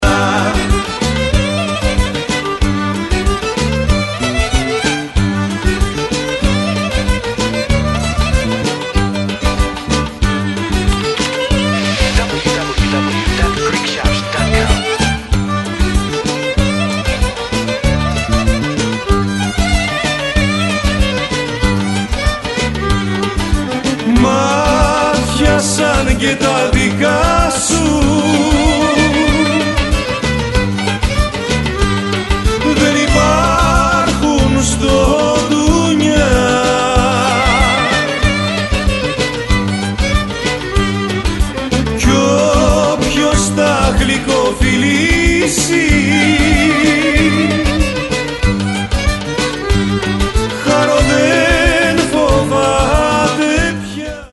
traditional Greek folk music
KALAMATIANA / LAIKODIMOTIKA